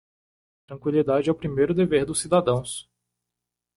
Read more Verb Noun Frequency B1 Pronounced as (IPA) /deˈve(ʁ)/ Etymology Inherited from Latin dēbeō In summary From Old Galician-Portuguese dever, from Latin dēbēre (“to owe”).